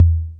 808-Tom1.wav